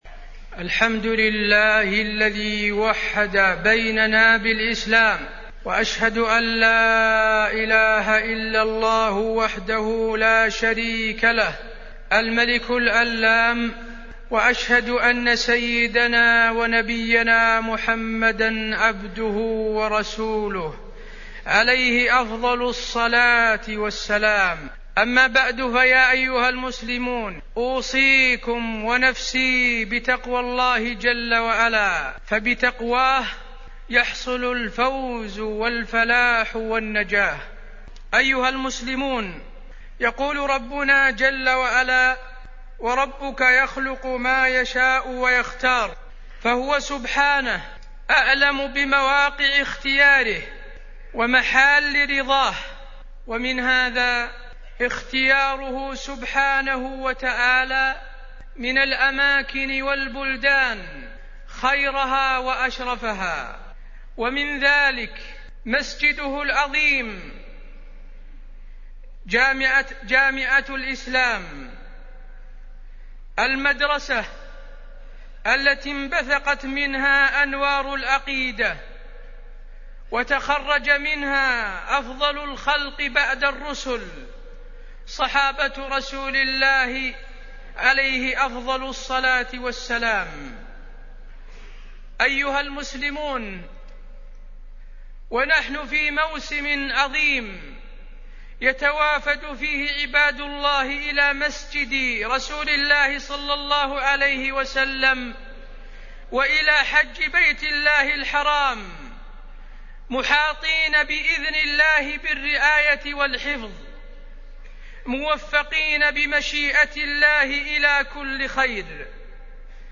تاريخ النشر ١٧ ذو القعدة ١٤٢٧ هـ المكان: المسجد النبوي الشيخ: فضيلة الشيخ د. حسين بن عبدالعزيز آل الشيخ فضيلة الشيخ د. حسين بن عبدالعزيز آل الشيخ فضل المسجد النبوي الشريف